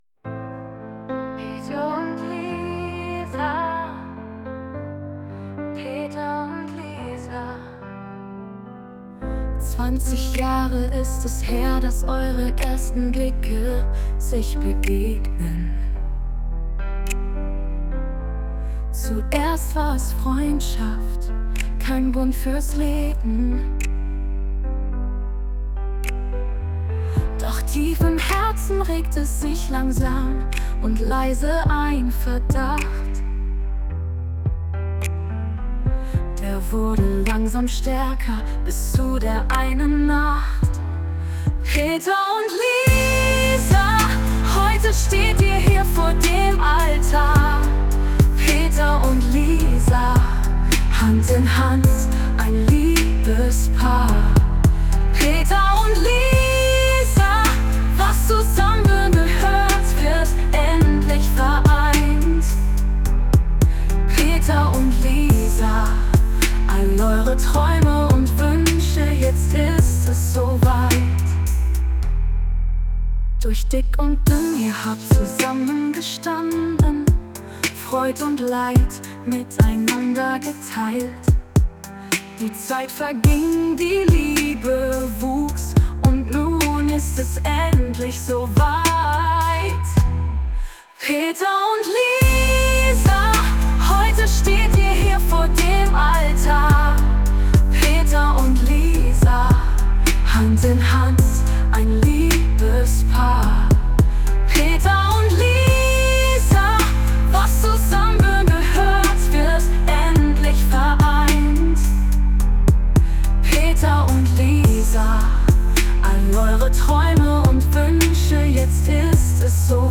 Hochzeit